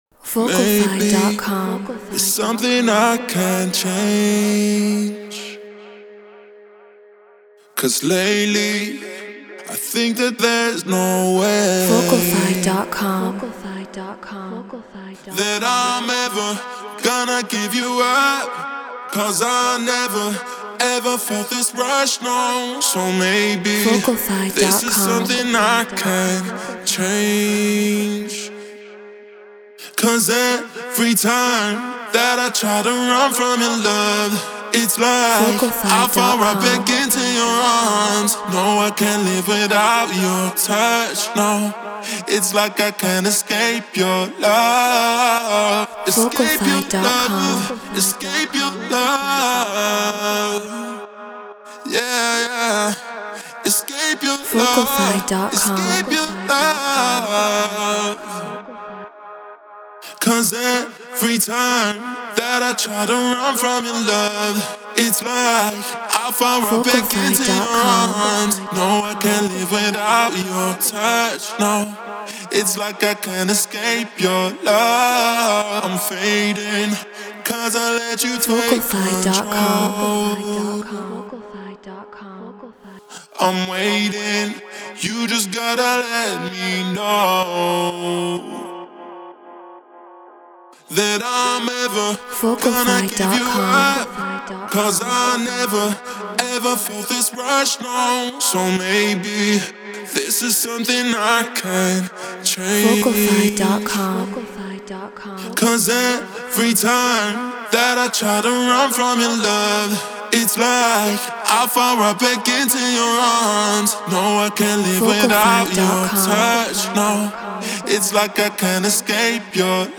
House 127 BPM G#min
Neumann TLM 103 Focusrite Scarlett Pro Tools Treated Room